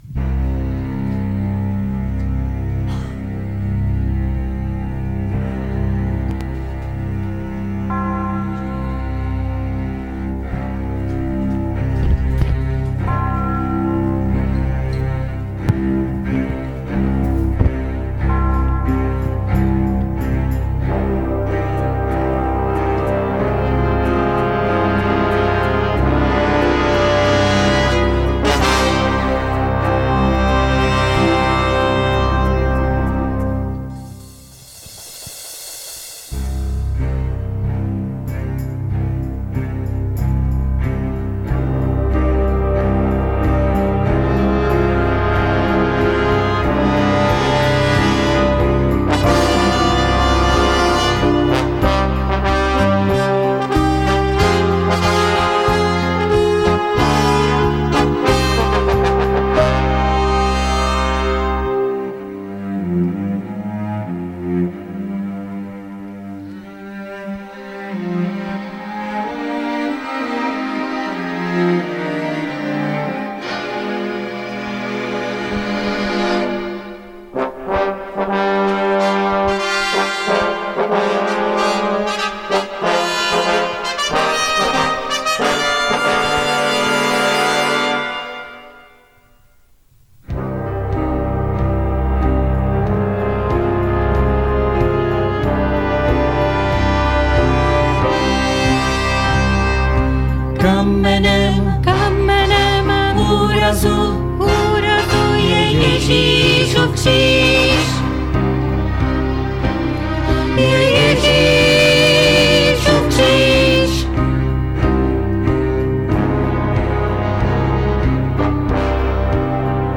skupina Credo
Kategorie: Nedělní bohoslužby